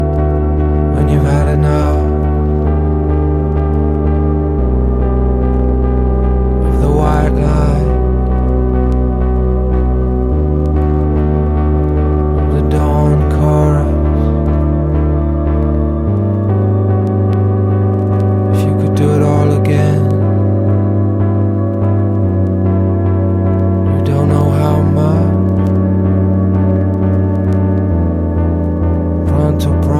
evocative, off-kilter songs